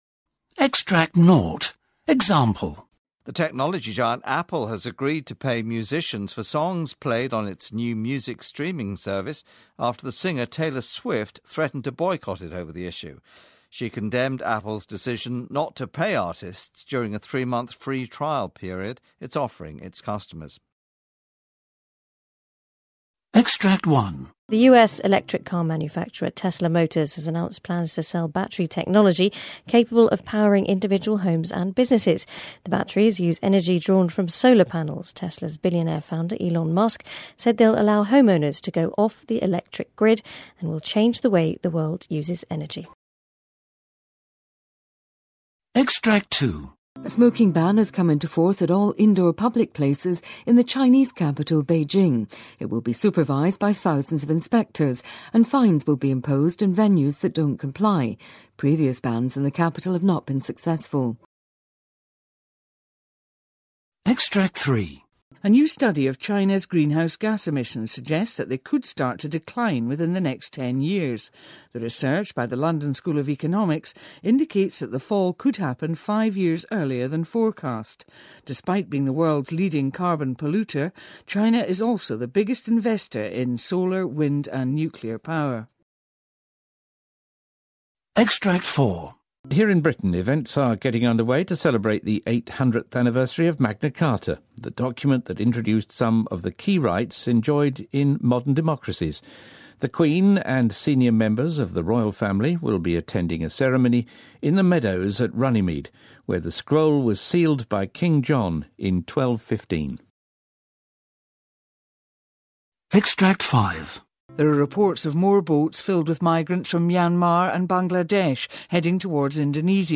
News report